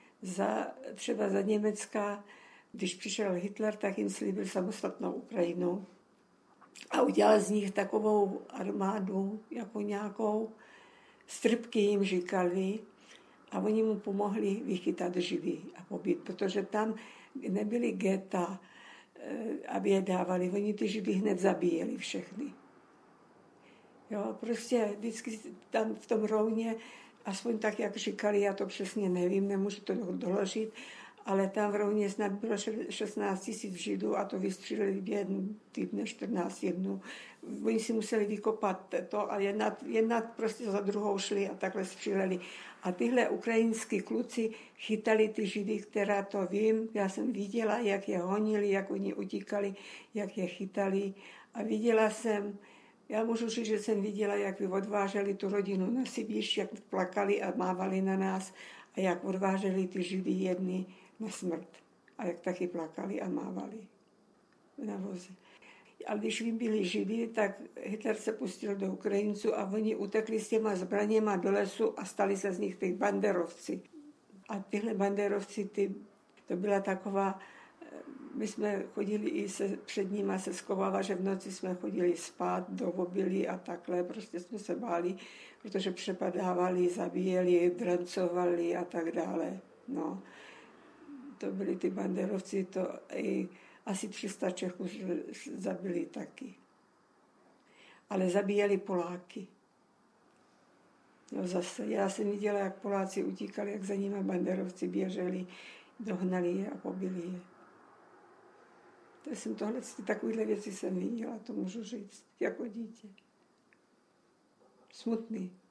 Rozhovory-Post Bellum © - Paměť národa